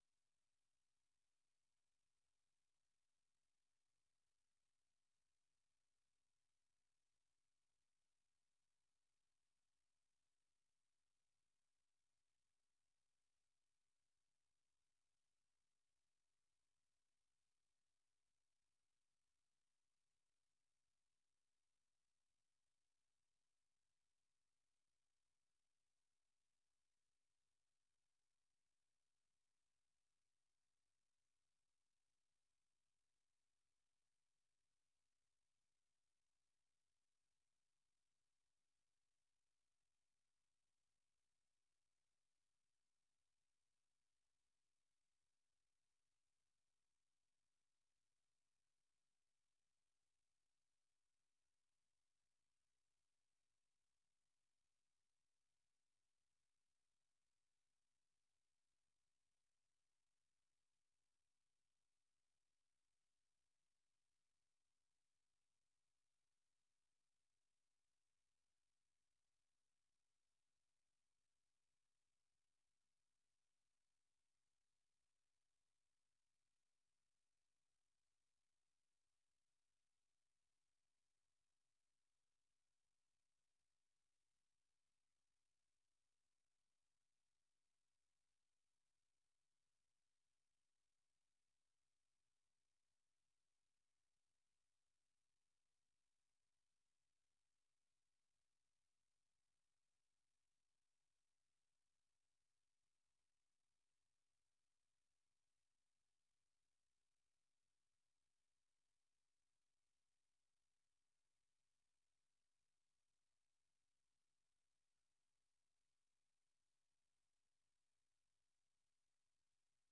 نخستین برنامه خبری صبح